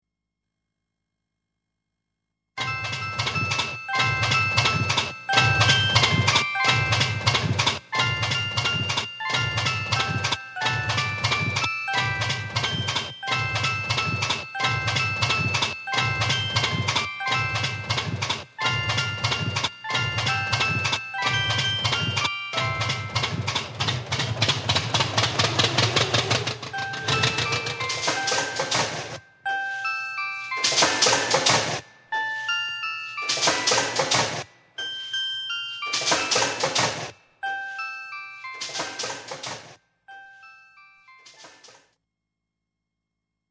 Real Farmers making Real Music using Real Tractors
tractor music